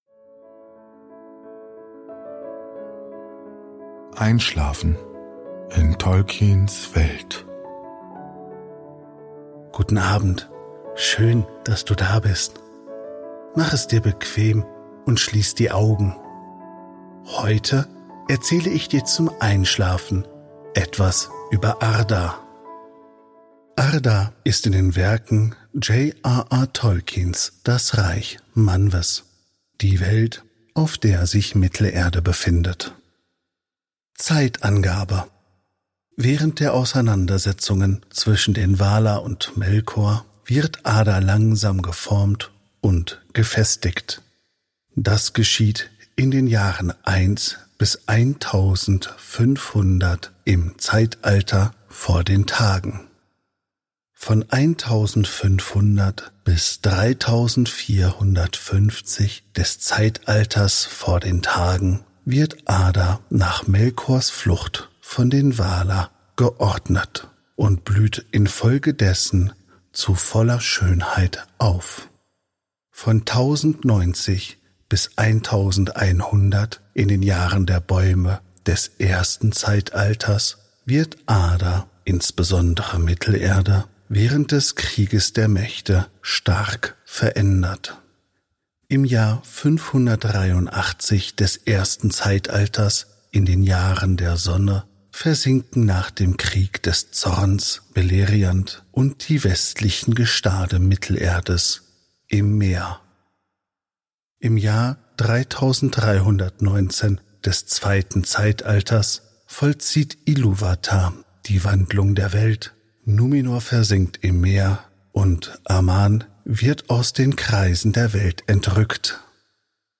Gutenachtgeschichten aus der Ardapedia